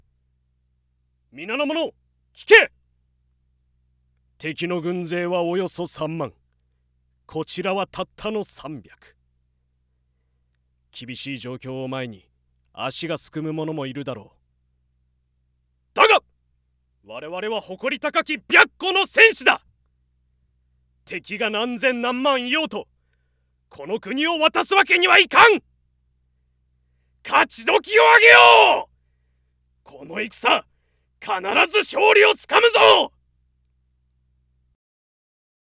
ボイスサンプル
男性４